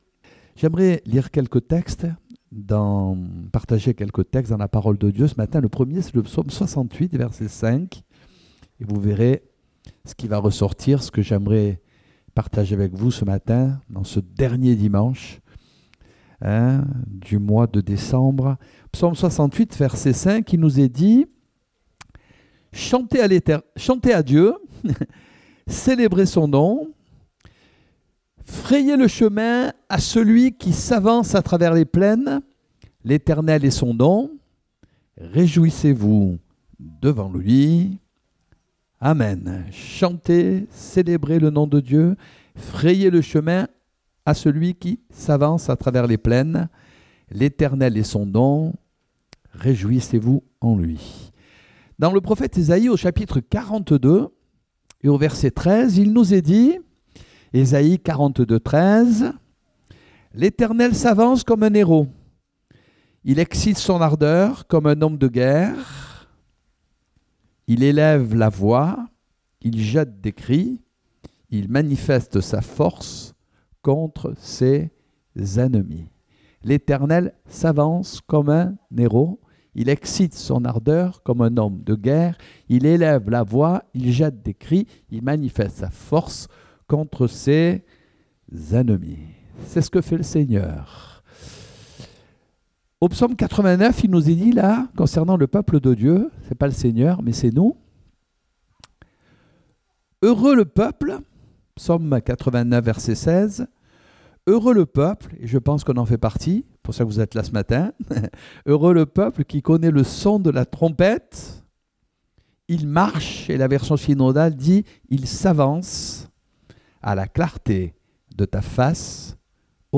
Date : 30 décembre 2018 (Culte Dominical)